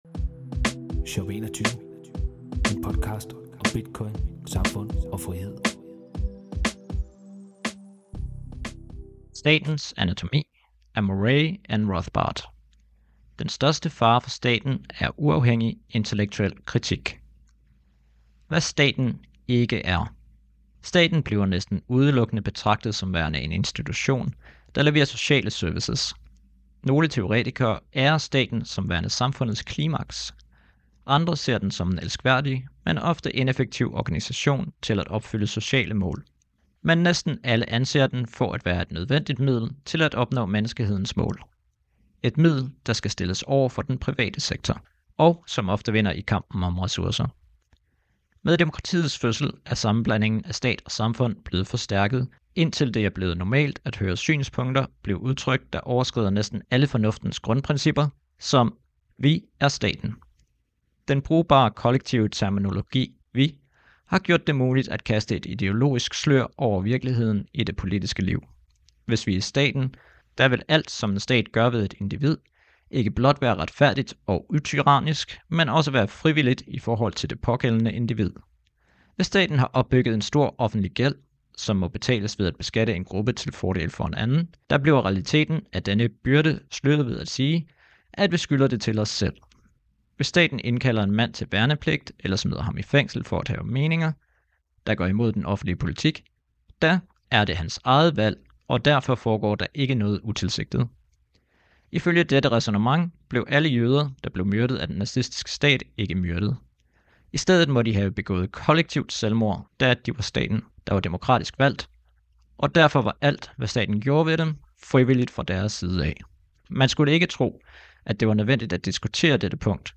This English audiobook edition is narrated